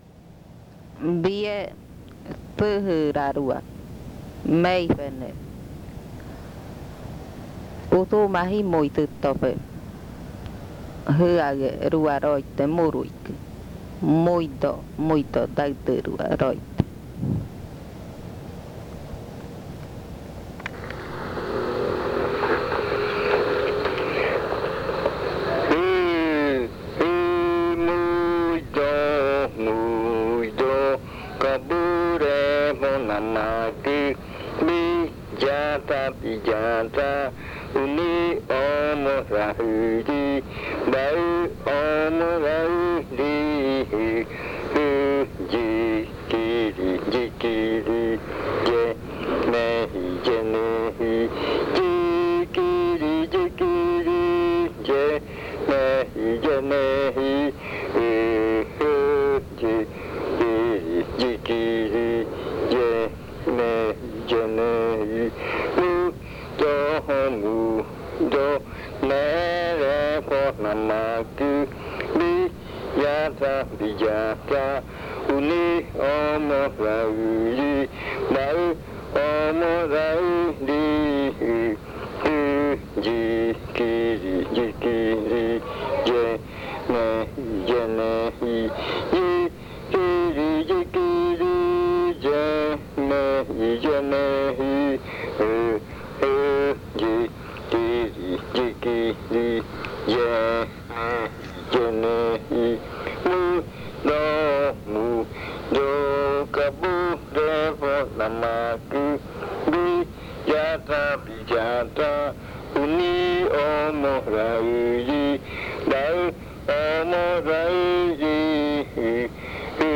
Leticia, Amazonas
Canción de madrugada 4:00 AM. Esta canción está relacionada con la pava silvestre y el zancudo.